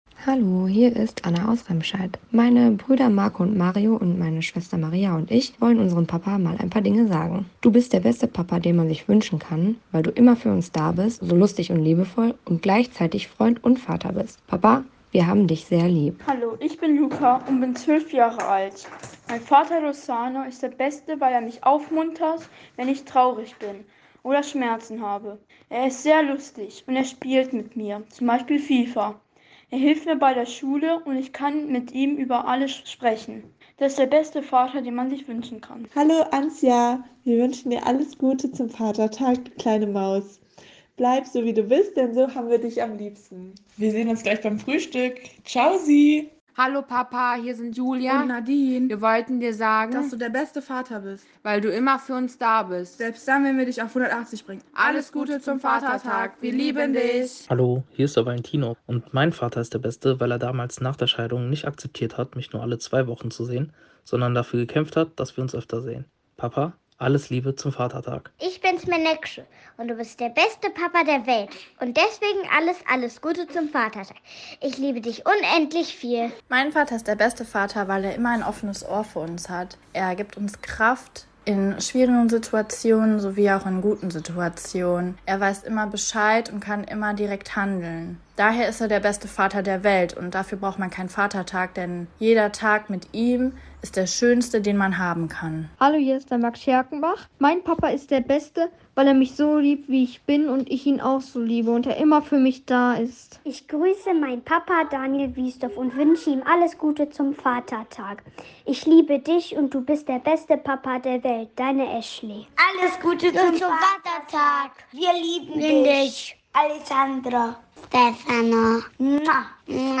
Zum Vatertag haben uns soviele Sprachnachrichten erreicht, dass wir am Ende der Aktion gar nicht mehr alle im Programm unterbringen konnten. Die gesendeten Grüße könnt ihr hier noch einmal nachhören.